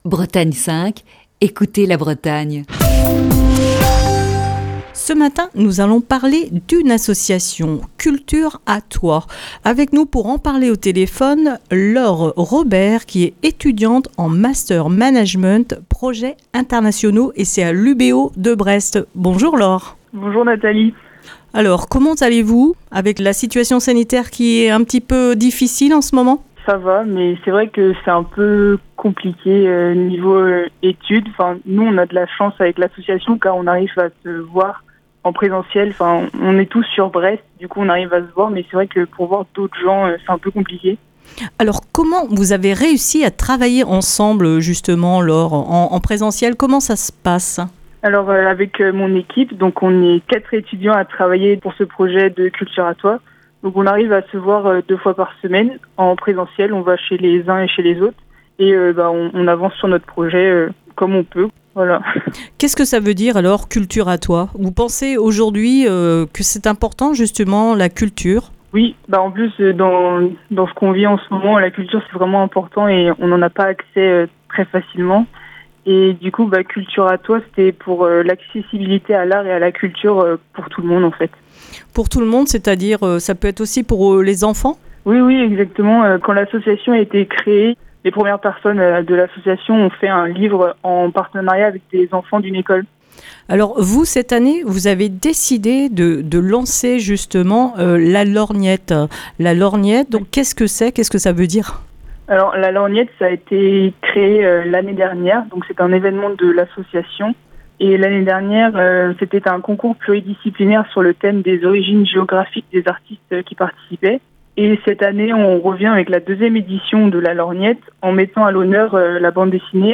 au téléphone